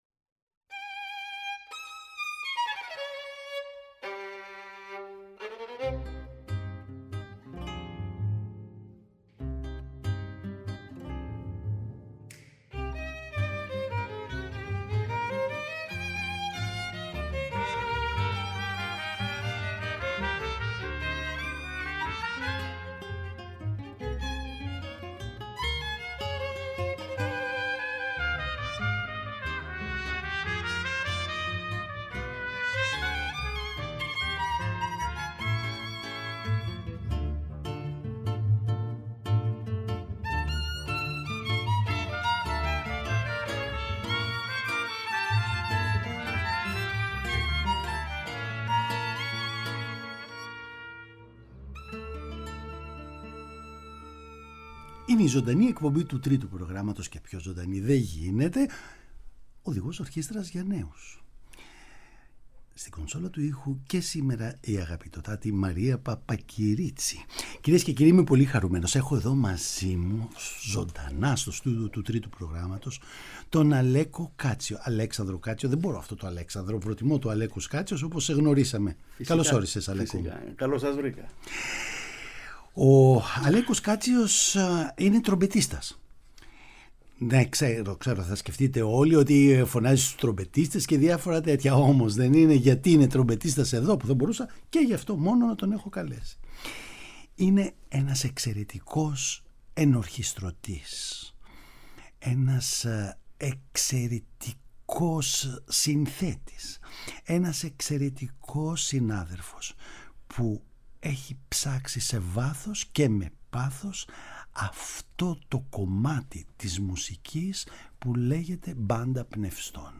Η παρουσία του στην εκπομπή μας θα είναι αφορμή να ακούσουμε όμορφες διασκευές για το δημοφιλές αλλά και λαϊκό είδος της φιλαρμονικής ορχήστρας πνευστών που κάθε δήμος με συνέπεια και σοβαρότητα οφείλει να καλλιεργεί προσφέροντας μουσική παιδεία και πνευματική απόλαυση στους δημότες το
Παραγωγή-Παρουσίαση: Νίκος Ξανθούλης